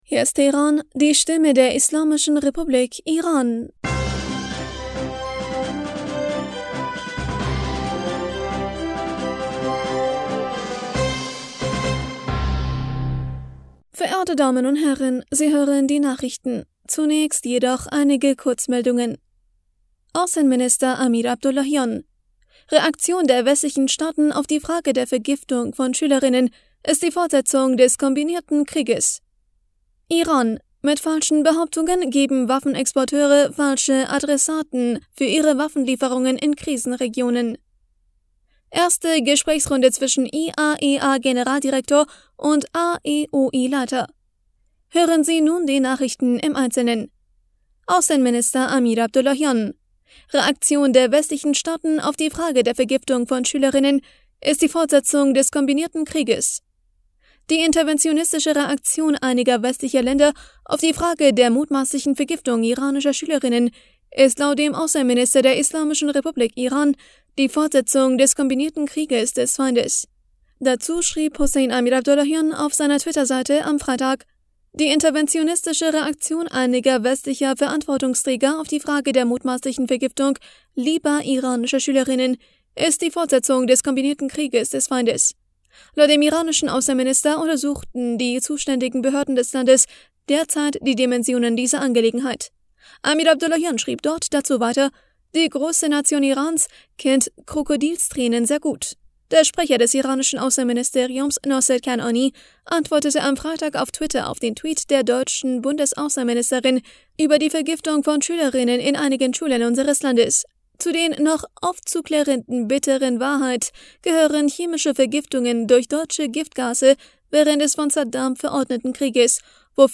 Nachrichten vom 04. März 2023